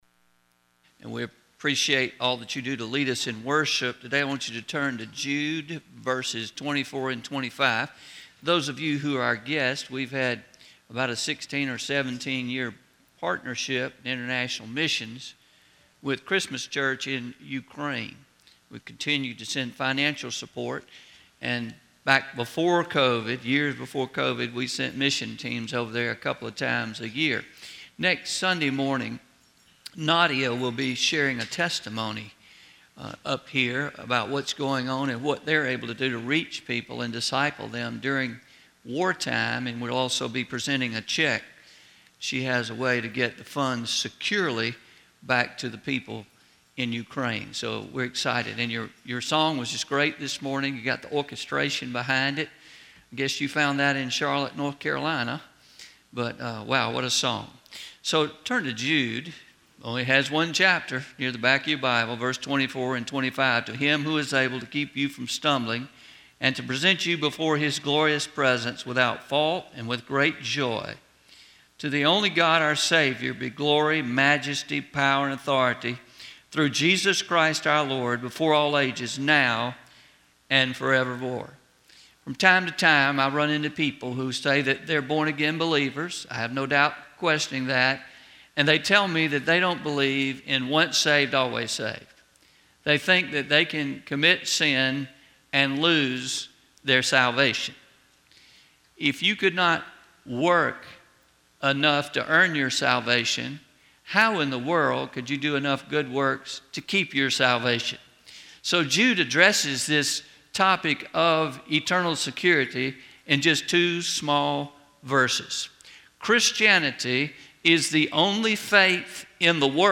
10-09-22am Sermon – Ditch Your Doubts (Combined Service)